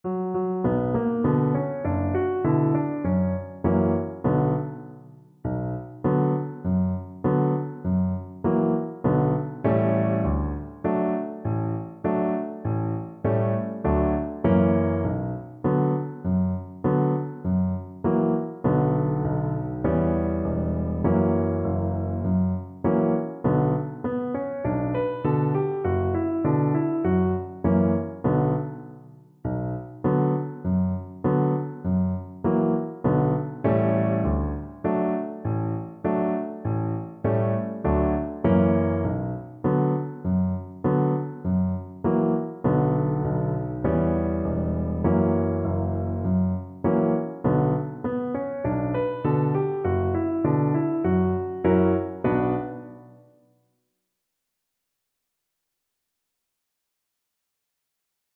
Cello
B minor (Sounding Pitch) (View more B minor Music for Cello )
Moderato
4/4 (View more 4/4 Music)
Traditional (View more Traditional Cello Music)